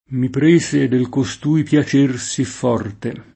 costui [koSt2i] pron.